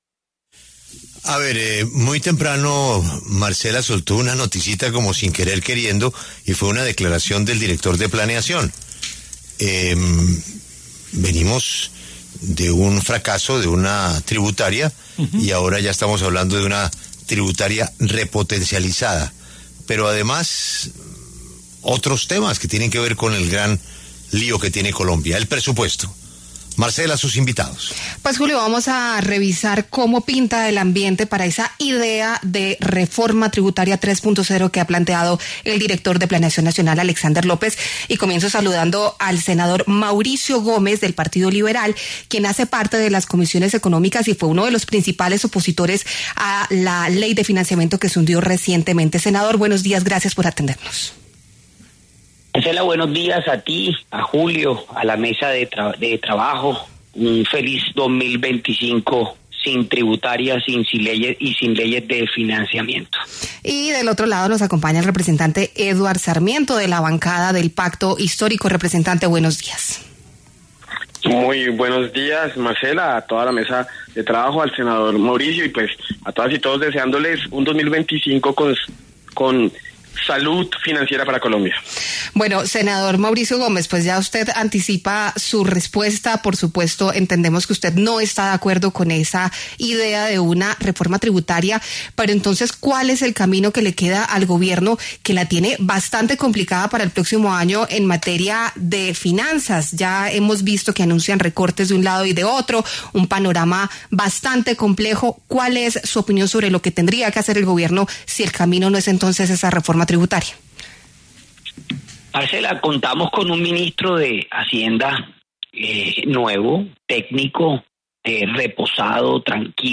Debate: ¿Hay ambiente en el Congreso para discutir una reforma tributaria 3.0?
En La W, los congresistas Mauricio Gómez y Eduard Sarmiento se pronunciaron a propósito del anuncio del director del DNP, Alexander López, de que se expedirá el decreto de aplazamiento de $12 billones en recursos tras la negativa del Congreso a una ley de financiamiento.